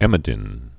(ĕmə-dĭn)